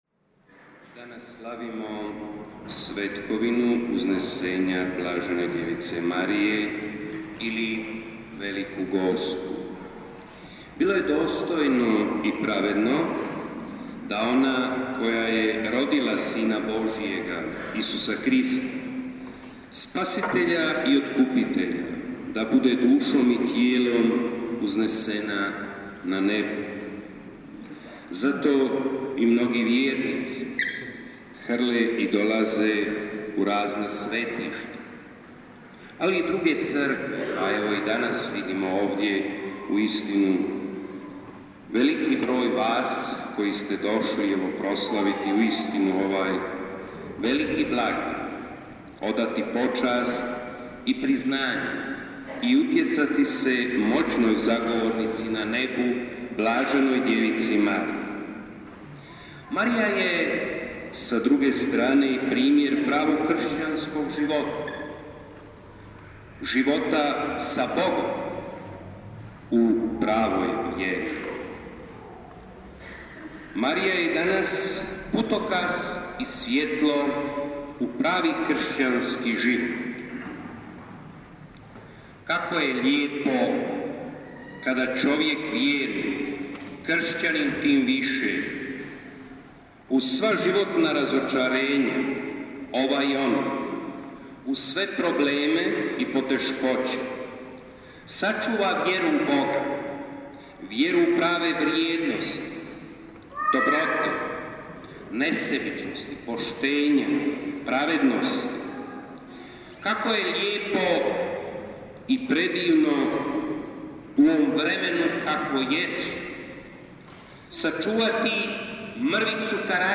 Svetkovina UZNESENJA BDM VELIKA GOSPA, mjesto događanja Župna Crkva.
PROPOVJED: